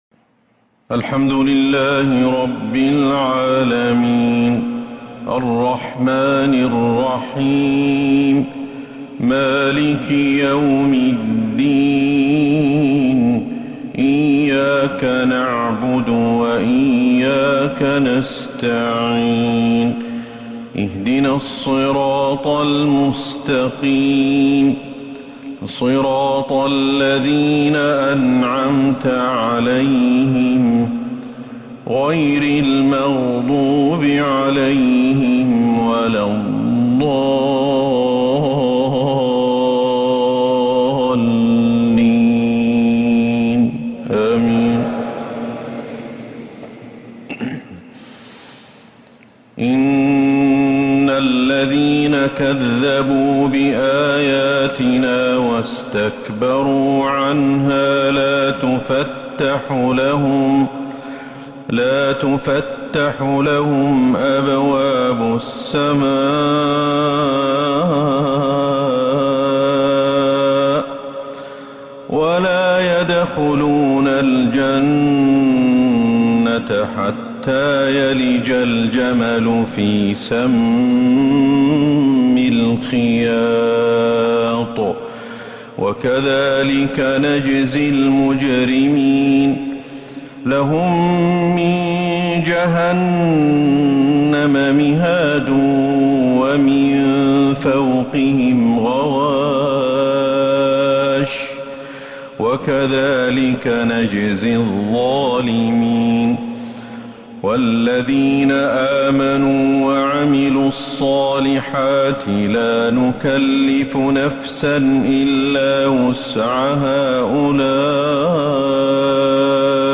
عشاء الثلاثاء 17 ربيع الأول 1447هـ من سورة الأعراف 40-51 | lsha Prayer from Surah Al-A'raf 9-9-2025 > 1447 🕌 > الفروض - تلاوات الحرمين